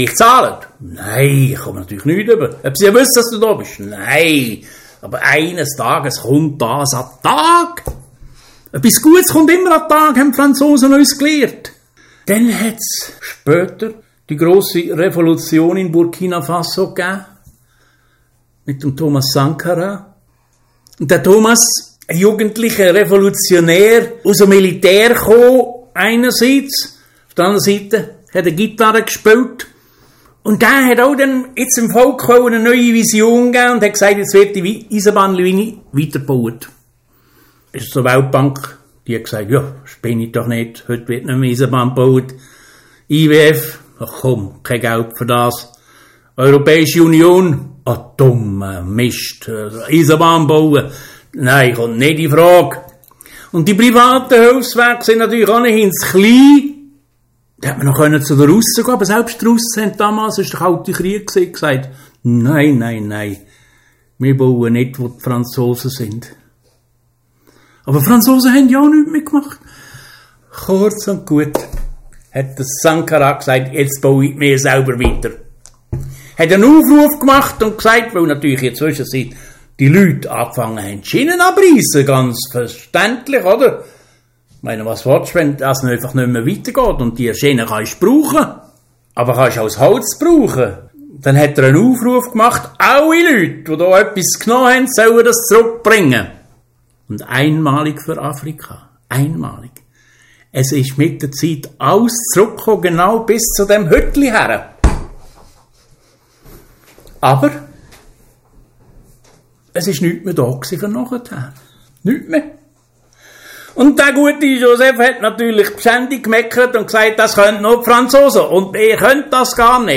Al Imfeld erzählt Geschichten